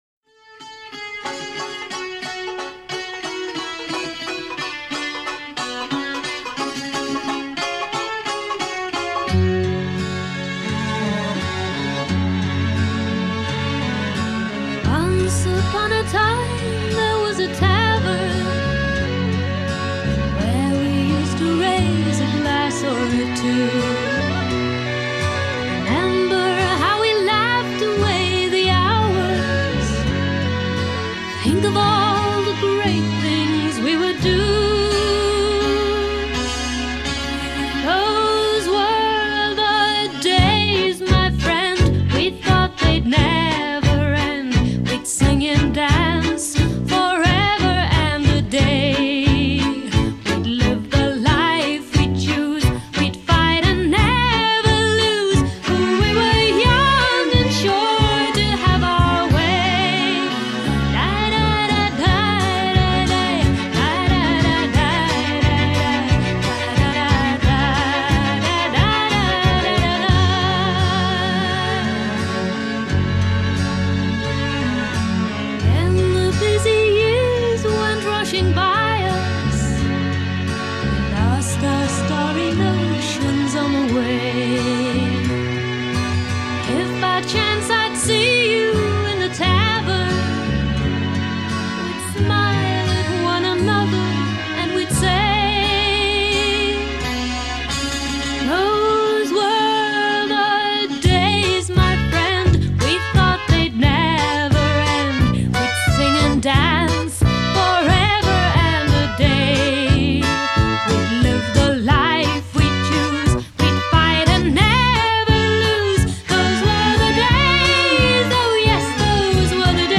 The 18 year-old Welsh singer